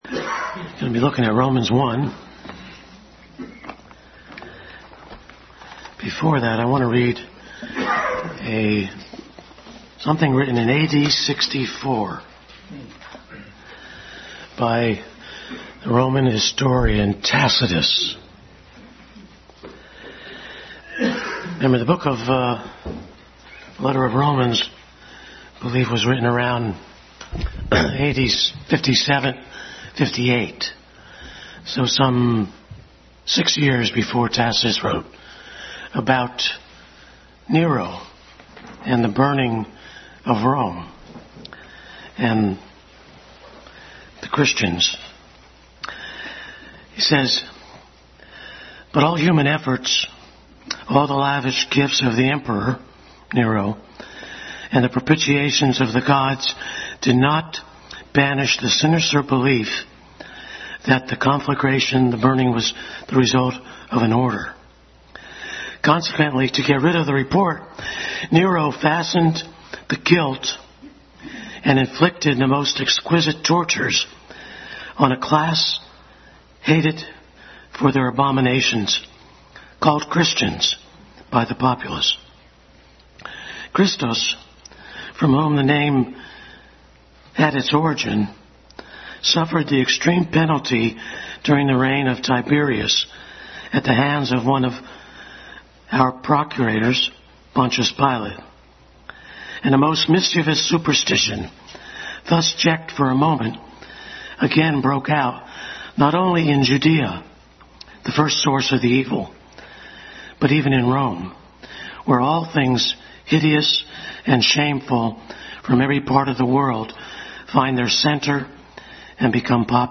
Adult Sunday School Class continued study in the book of Romans.